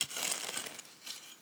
Babushka / audio / sfx / Farming / SFX_Harke_03_Solo_Reverb.wav
SFX_Harke_03_Solo_Reverb.wav